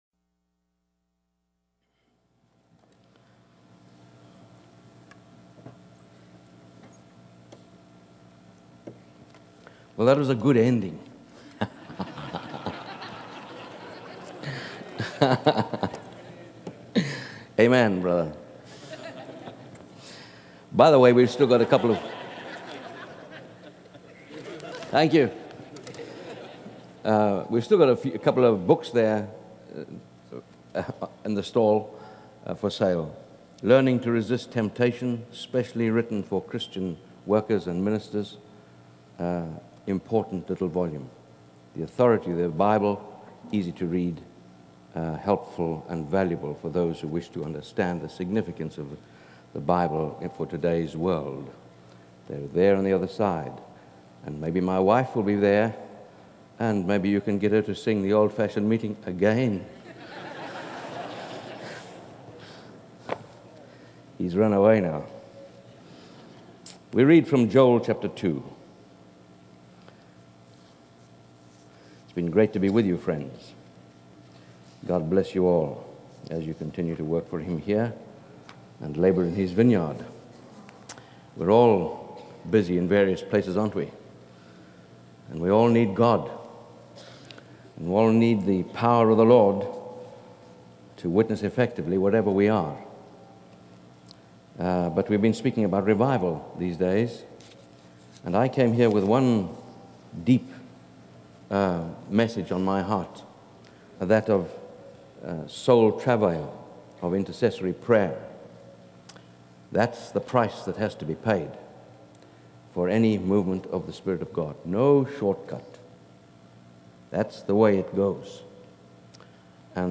The sermon emphasizes the importance of soul travail, or intercessory prayer, as the price of revival, and highlights Joel's message of genuine repentance and God's promise of revival.